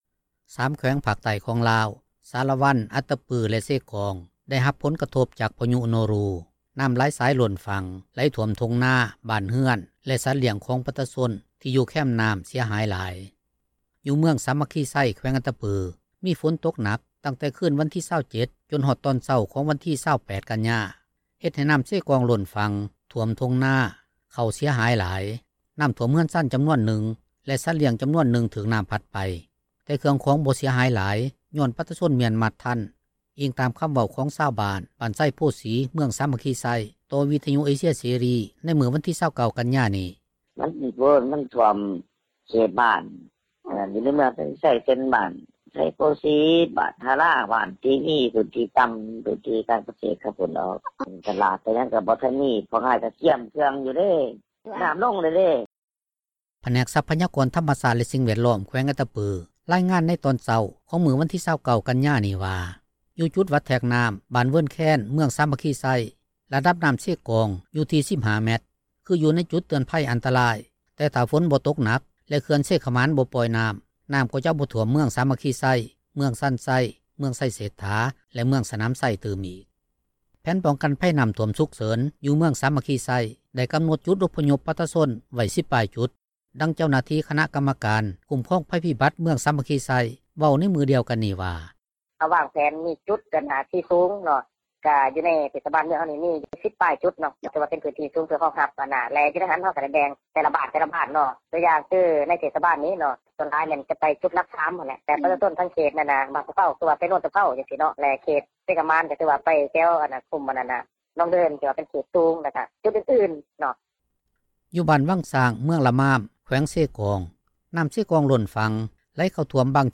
ດັ່ງຊາວບ້ານ ບ້ານນາດອນແກ້ວ ເວົ້າໃນມື້ດຽວກັນນີ້ວ່າ:
ດັ່ງປະຊາຊົນ ຢູ່ບ້ານແກ້ງຫວດ ເວົ້າໃນມື້ດຽວກັນນີ້ວ່າ: